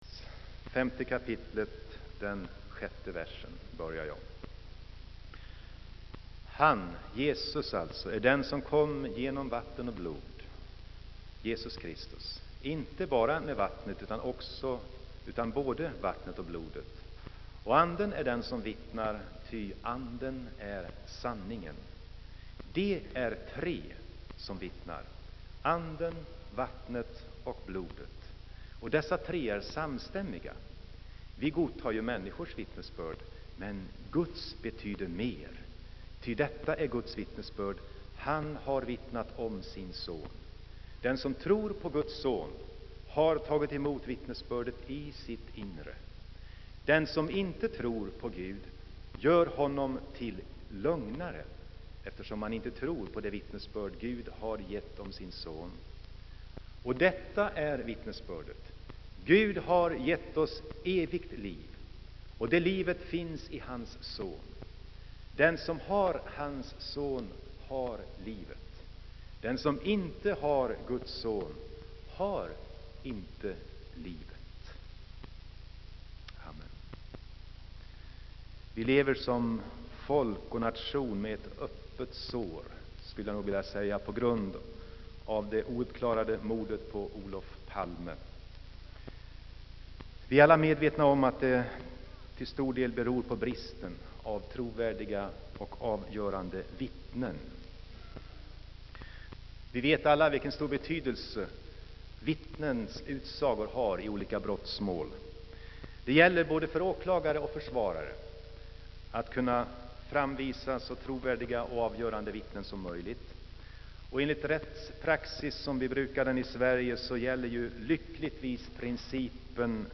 Inspelad i Saronkyrkan, Göteborg.